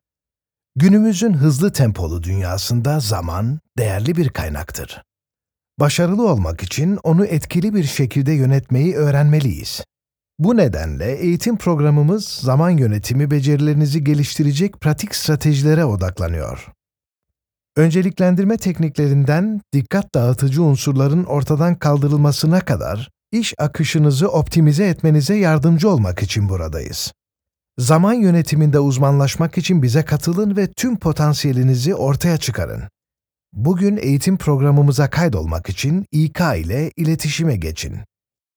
Male
Adult (30-50)
Corporate